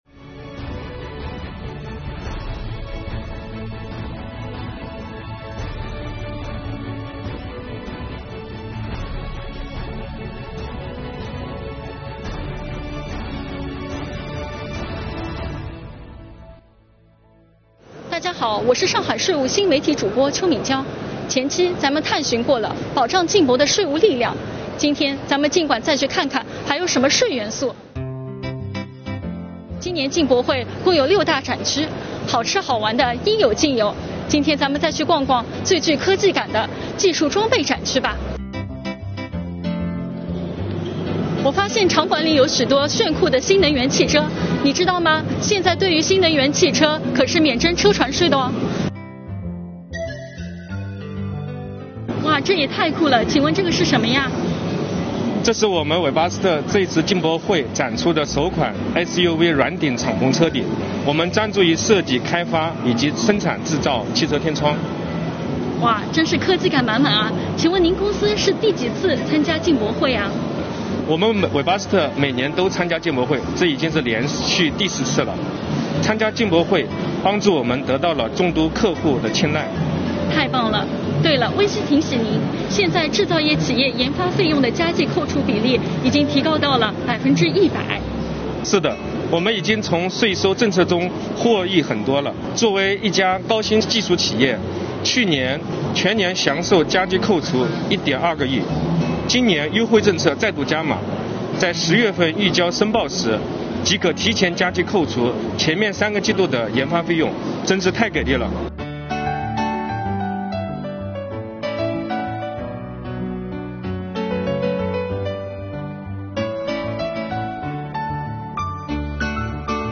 我们的主播走进展厅，带大家来定格进博会上的精彩瞬间。
前期，我们的税务干部向大家简要介绍了本次进博会的税收保障服务。让主播带着大家进馆继续探索吧！